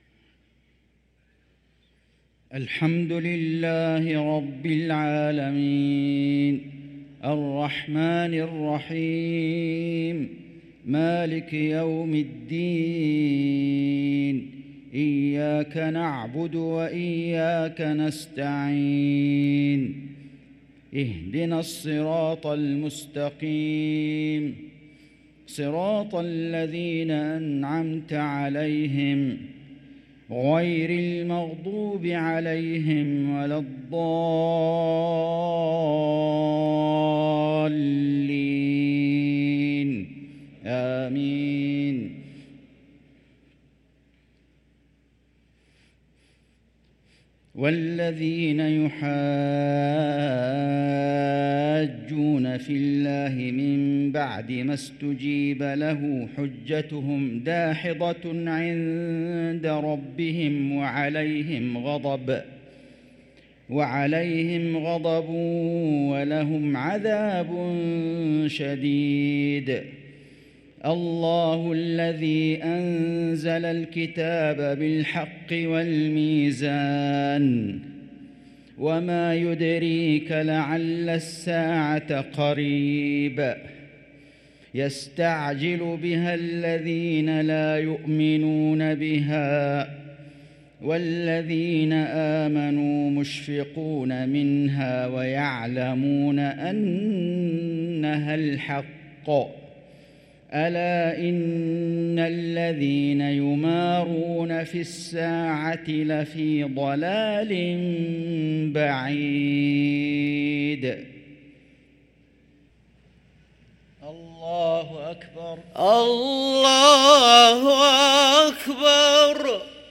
صلاة المغرب للقارئ فيصل غزاوي 1 صفر 1445 هـ
تِلَاوَات الْحَرَمَيْن .